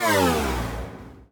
UIMvmt_Power Down Denied 02.wav